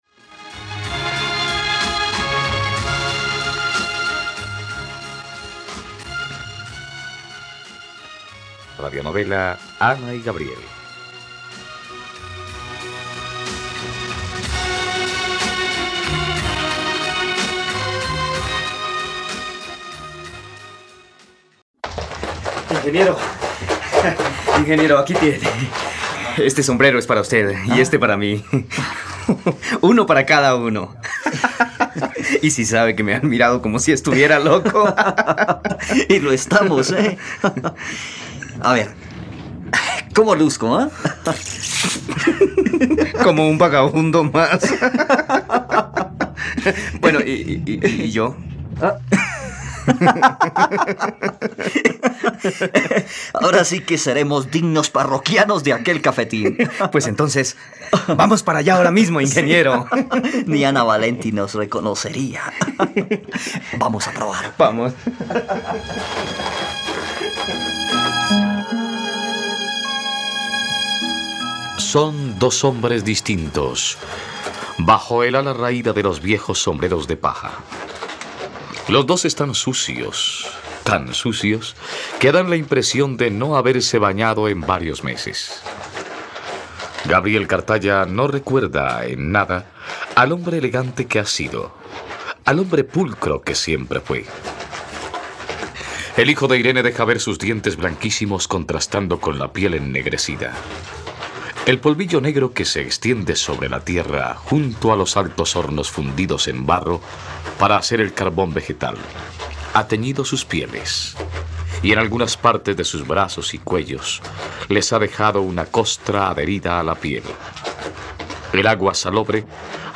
..Radionovela. Escucha ahora el capítulo 118 de la historia de amor de Ana y Gabriel en la plataforma de streaming de los colombianos: RTVCPlay.